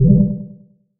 Updated notification sounds